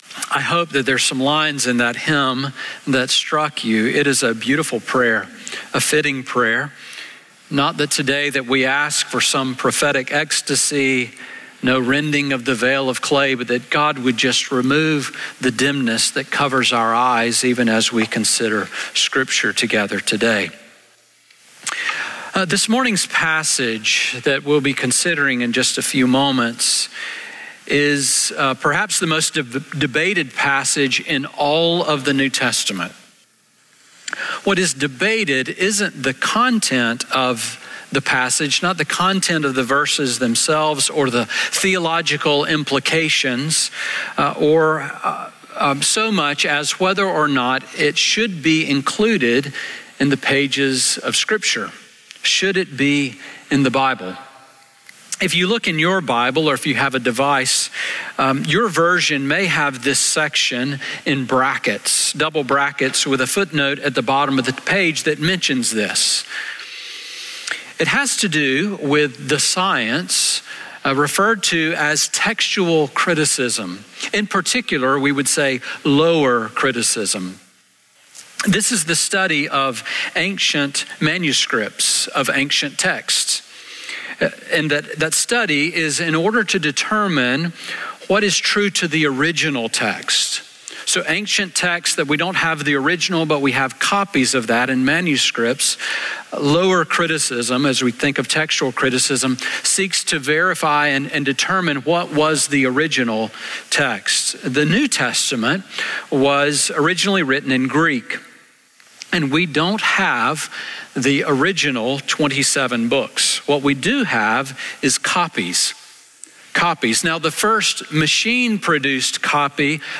Sermon on John 7:53-8:11 from December 14